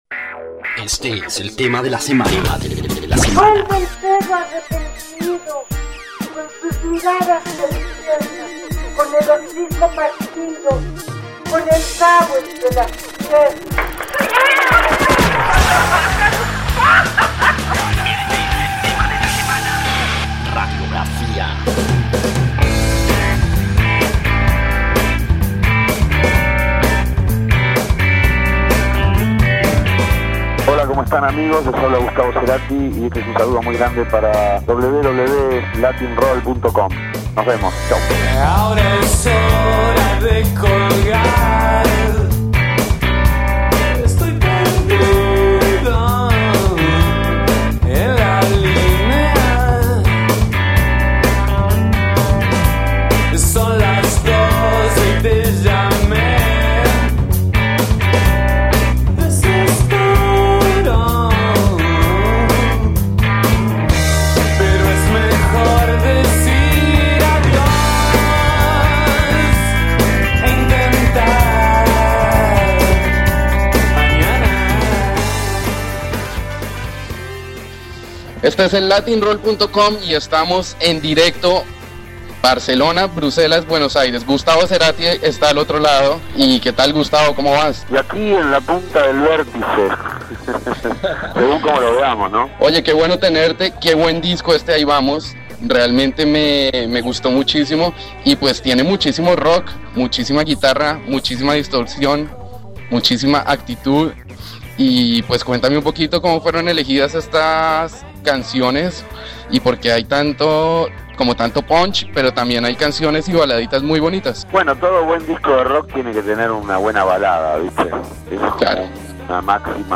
Latin-Roll - Entrevistas Gustavo Cerati Reproducir episodio Pausar episodio Mute/Unmute Episode Rebobinar 10 segundos 1x Fast Forward 30 seconds 00:00 / Suscribir Compartir Feed RSS Compartir Enlace Incrustar